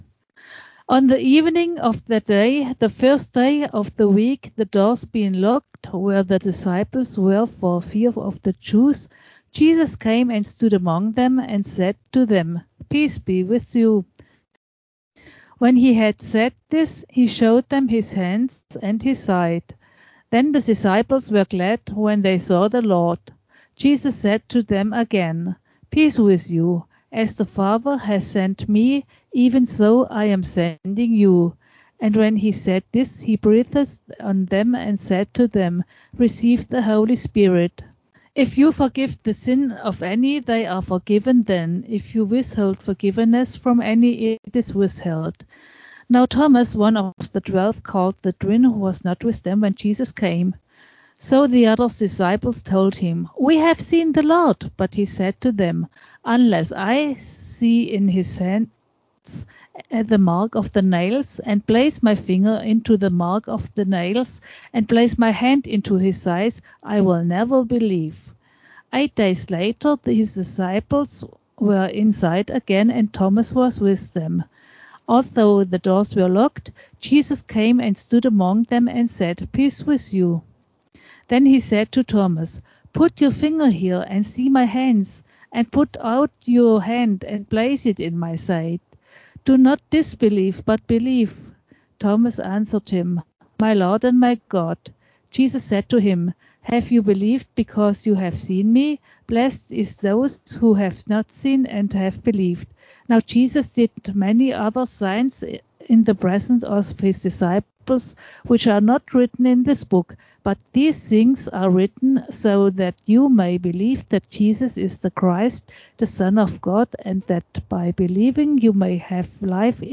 The following is my interview with sister on related issues.